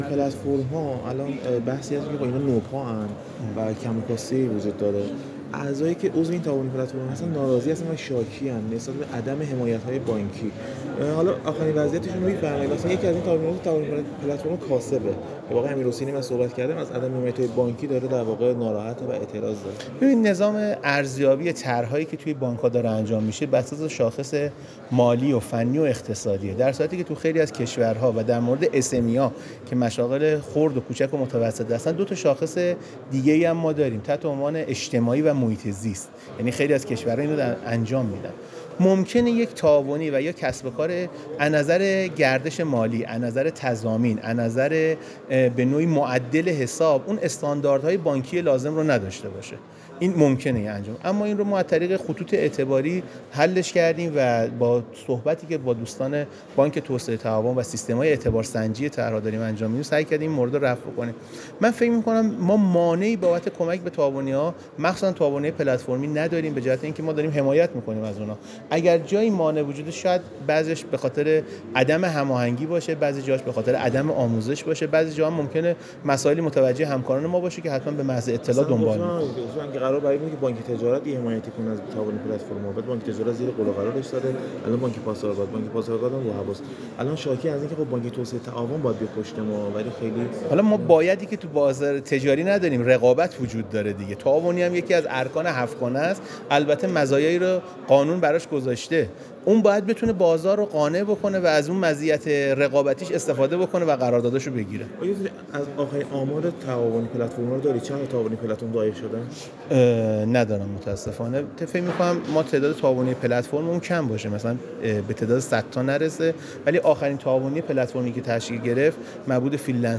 مسکنی در گفت‌وگو با ایکنا: